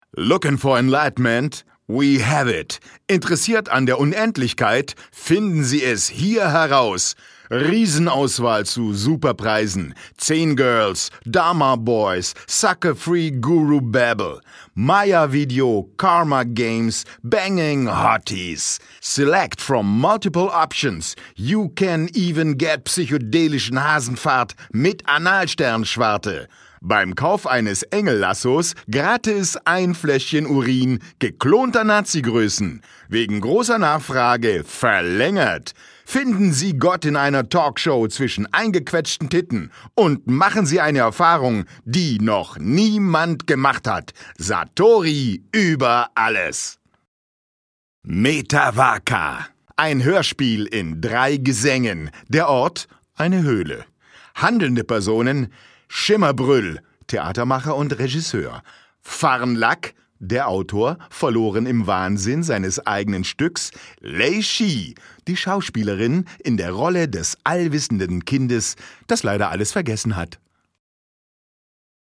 Hörspiel
Uraufführung bei der Gmünder Art am Samstag, 24.6.2017